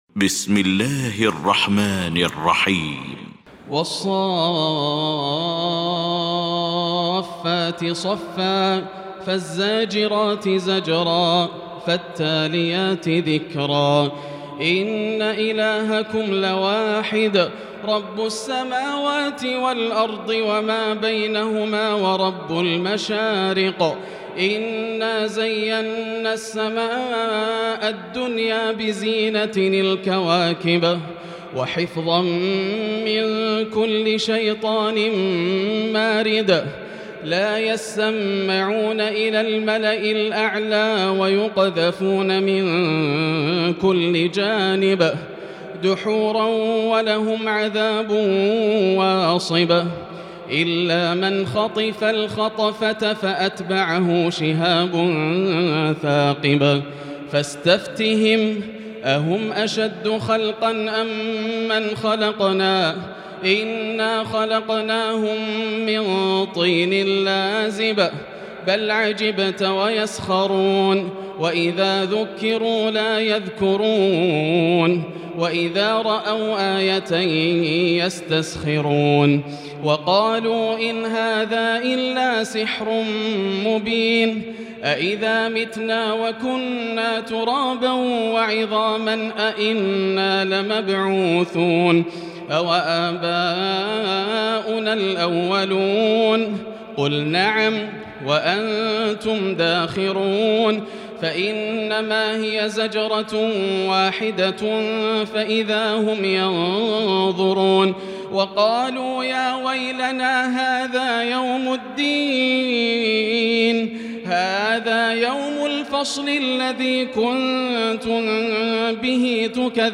المكان: المسجد الحرام الشيخ: فضيلة الشيخ ياسر الدوسري فضيلة الشيخ ياسر الدوسري الصافات